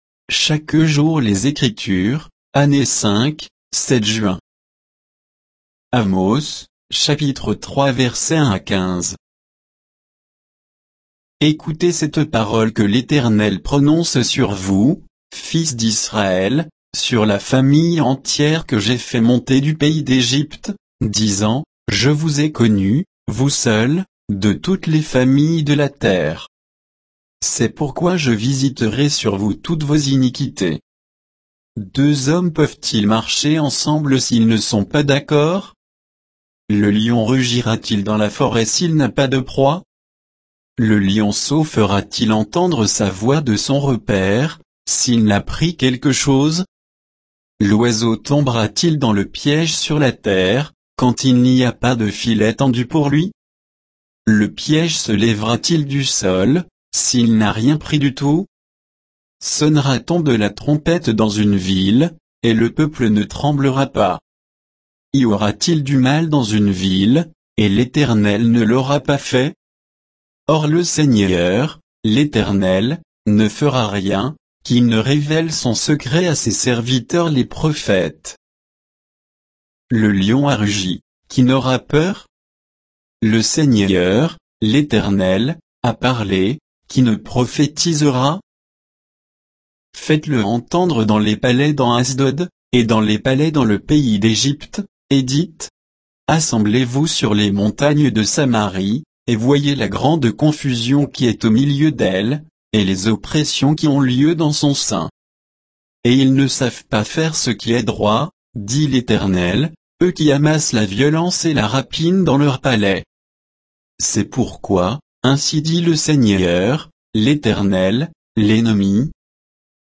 Méditation quoditienne de Chaque jour les Écritures sur Amos 3, 1 à 15